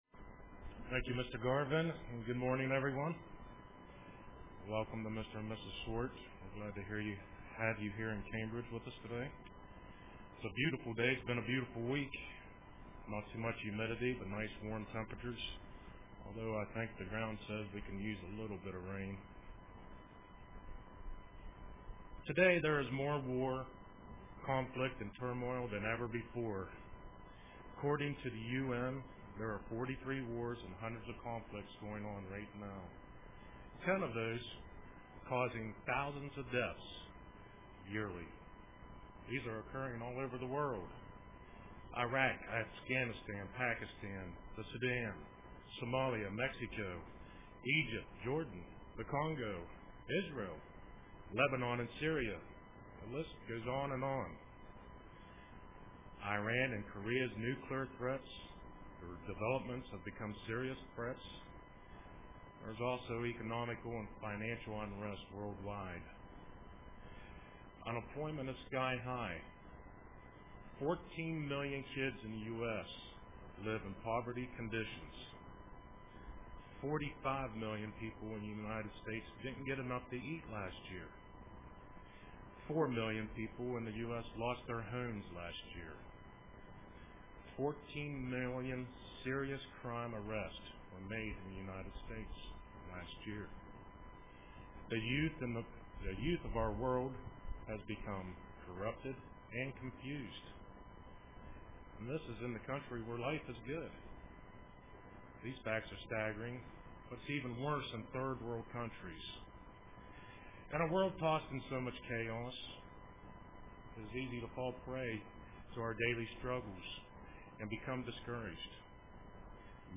Print Encouragement UCG Sermon Studying the bible?